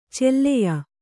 ♪ celleya